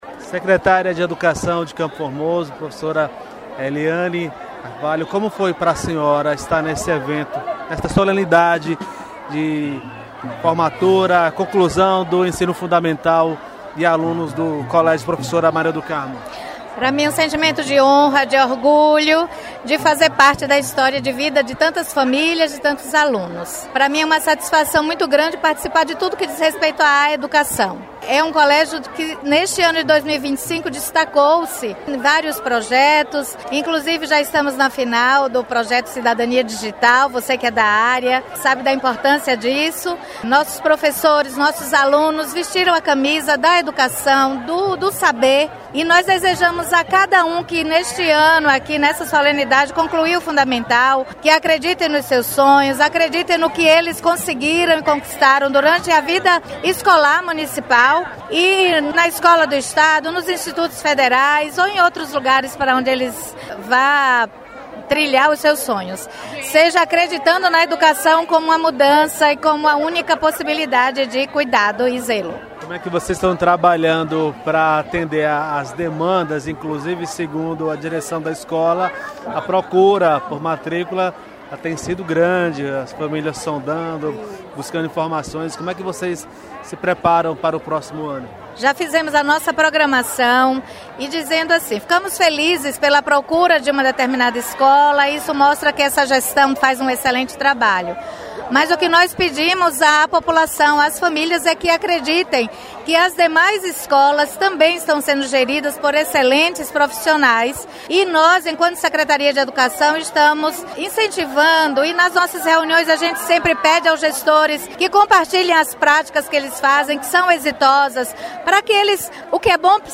Secretária de Educação do município de CFormoso, Eliane Costa – Conclusão do ensino fundamental dos alunos da escola Maria do Carmo – Radio 98 FM